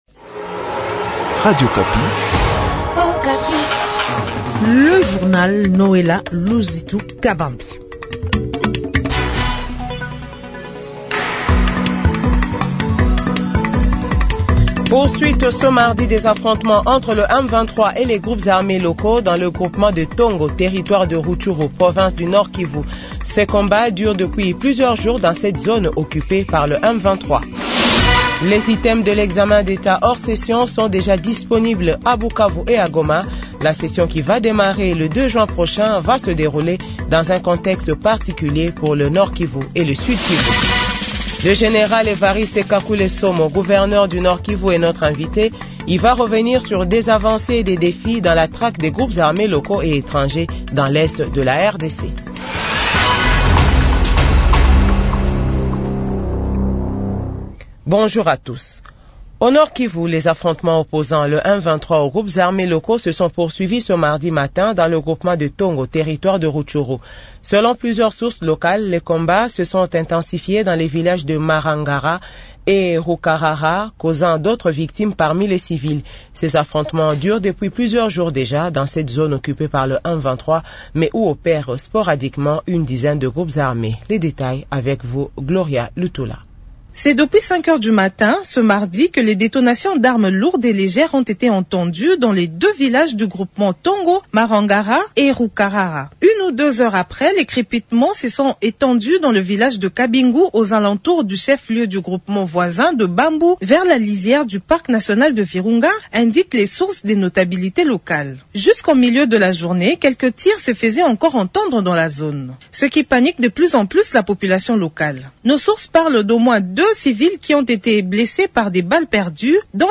Journal 15h